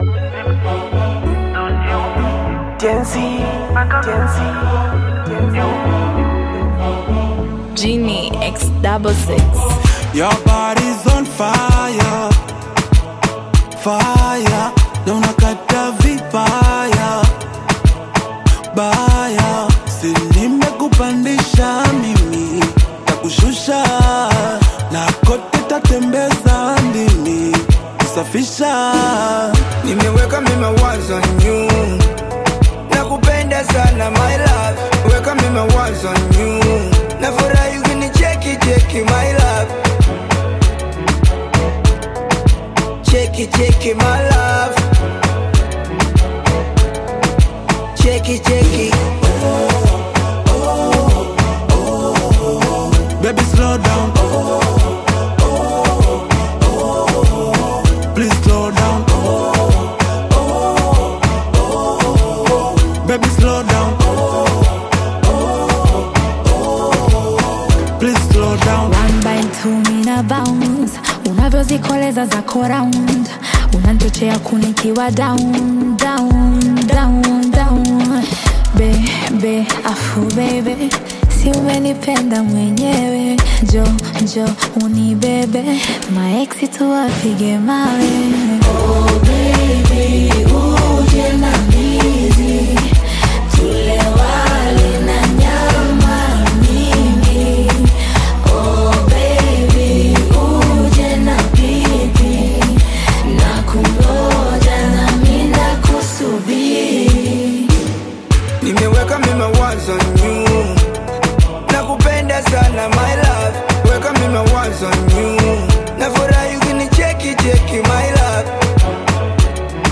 smooth and captivating track
With its catchy hook and relaxed groove